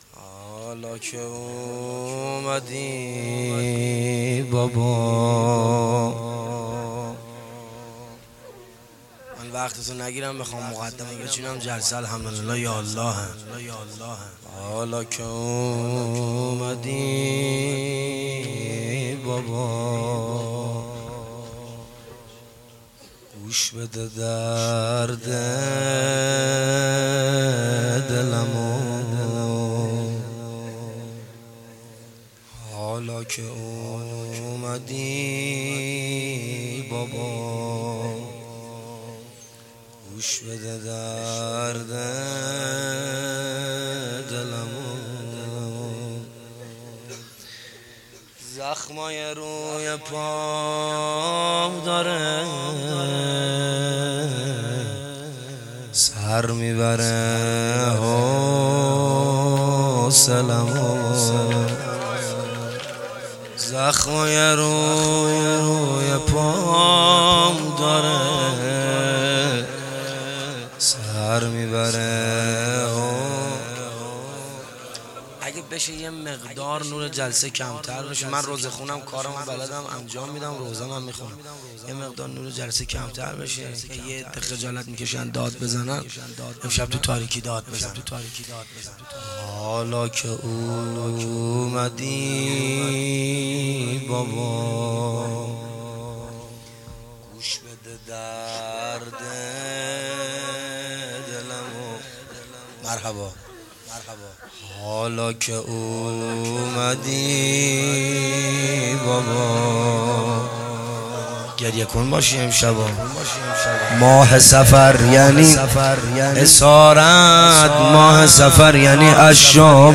هیئت عاشقان ولایت استهبان